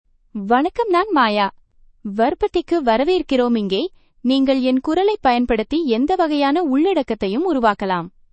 Maya — Female Tamil AI voice
Maya is a female AI voice for Tamil (India).
Voice sample
Female
Maya delivers clear pronunciation with authentic India Tamil intonation, making your content sound professionally produced.